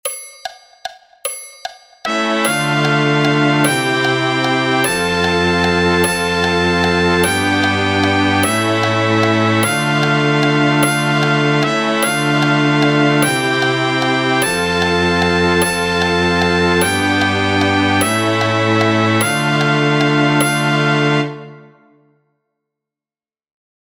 Here you can find a midi-file of each Guideline played on a trumpet with string accompaniment, so you can hear how your guideline relates to the rest. There is a percussion accompaniment for you to get a sense of the meter (how many beats there are in a bar).
tarantella_guidelines-E-intro-perc.mp3